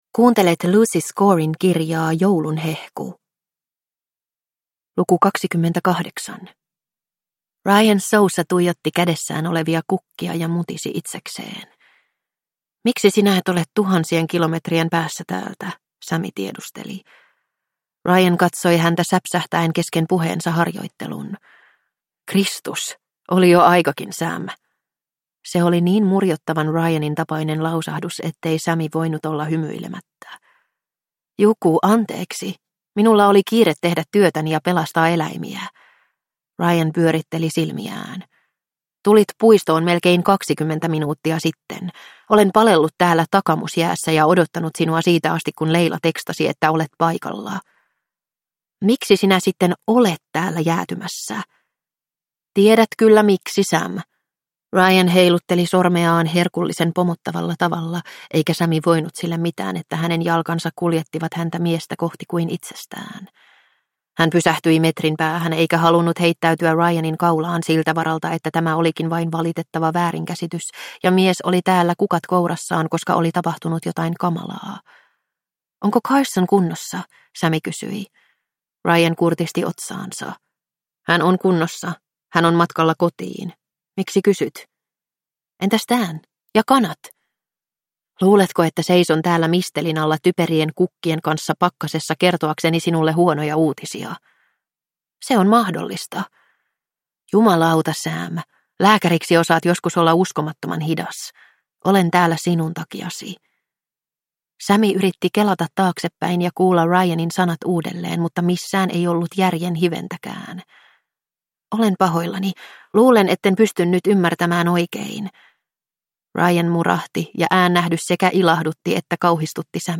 Joulun hehku - Luukku 23 – Ljudbok